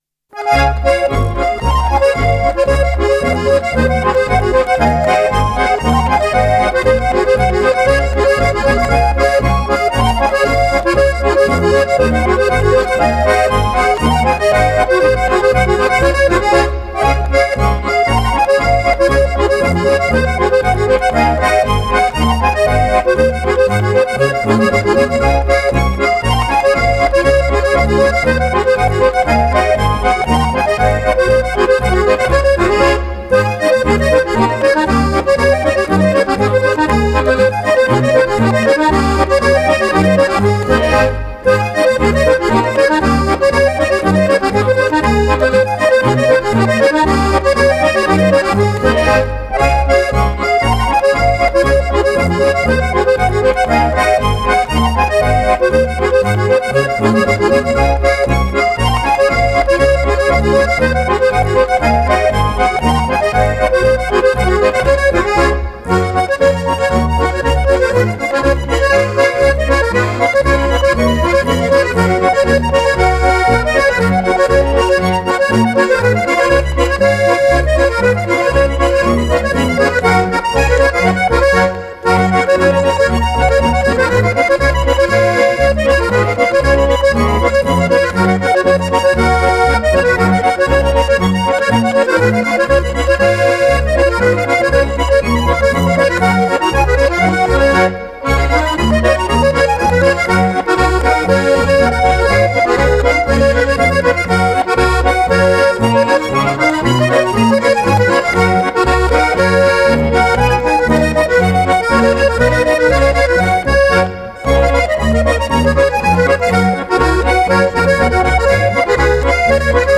Fluehnägeli. Schottisch.